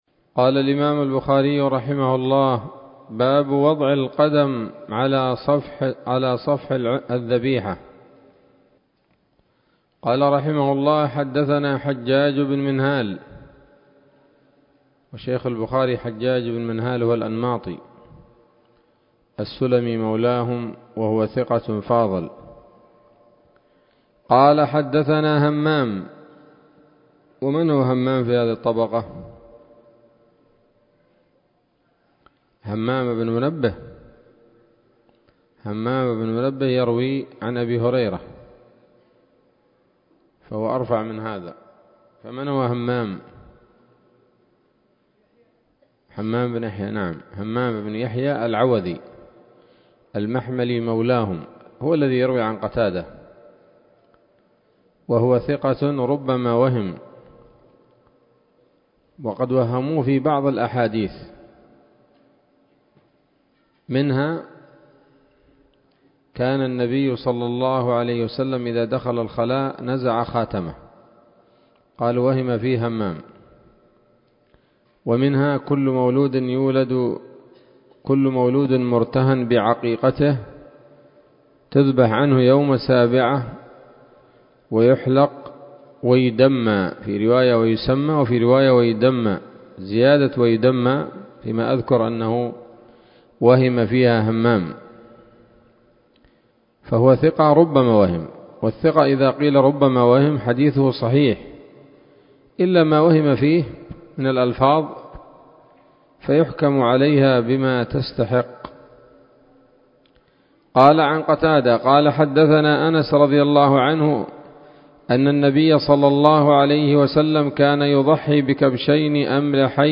الدرس الثاني عشر من كتاب الأضاحي من صحيح الإمام البخاري